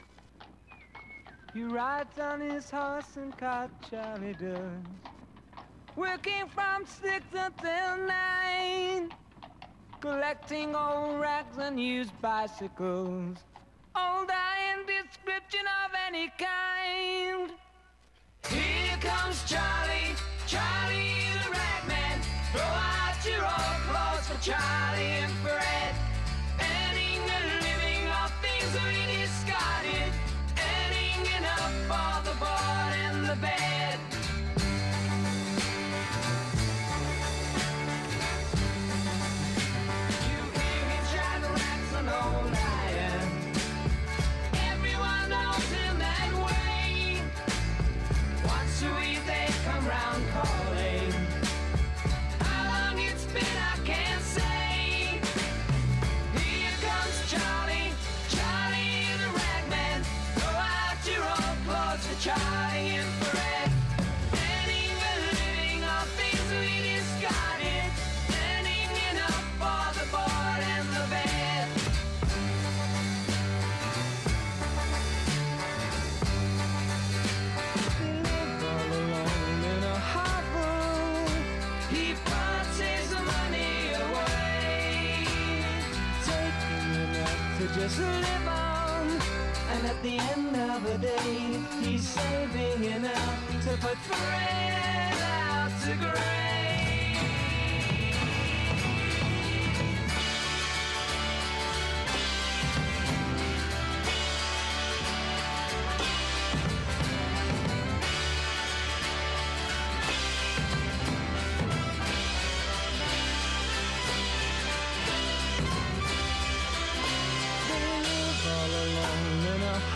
Our first genre special of the year, and what a groovy thing we had goin’!